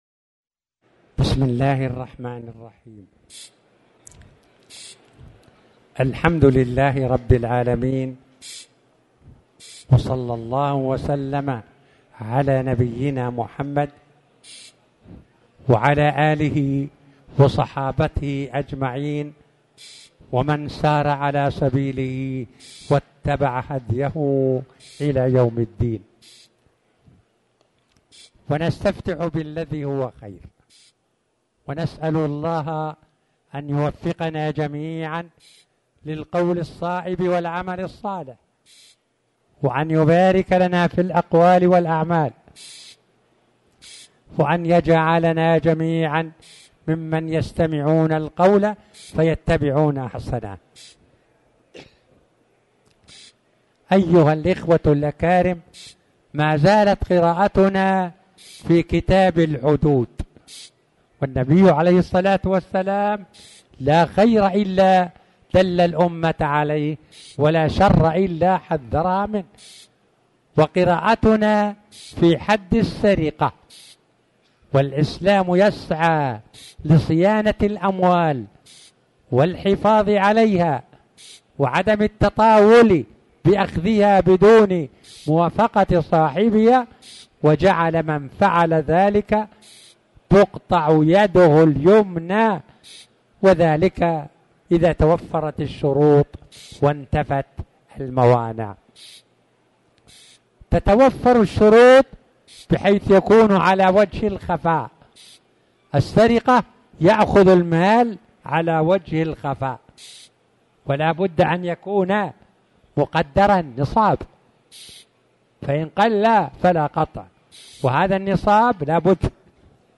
تاريخ النشر ٤ ربيع الأول ١٤٤٠ هـ المكان: المسجد الحرام الشيخ